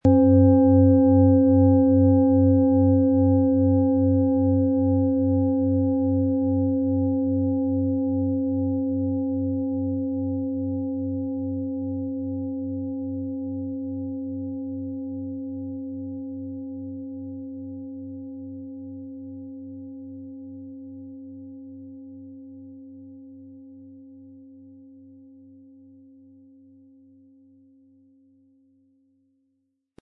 Planetenton
Von Meisterhand getrieben und somit von Hand gearbeitete Klangschale aus einem Traditionsbetrieb.
Ein die Schale gut klingend lassender Schlegel liegt kostenfrei bei, er lässt die Planetenklangschale Jupiter harmonisch und angenehm ertönen.
MaterialBronze